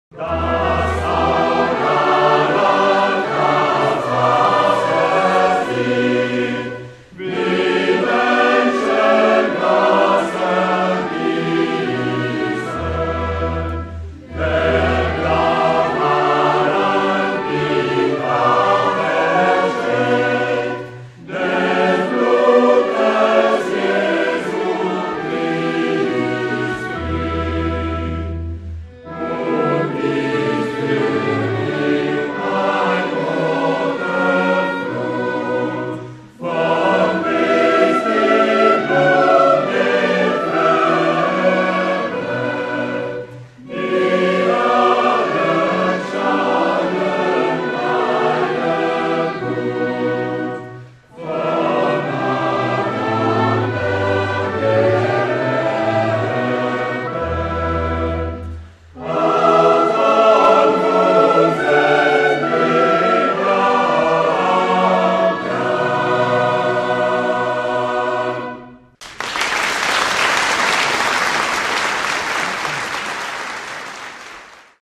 Concert "De Bach � Britten" avec l'Ensemble Instrumental "Archets pour un Espoir" Samedi 19 mars 2005 20h30 Eglise Notre Dame de la Paix MACON Extrait MP3 du concert: Choral Bach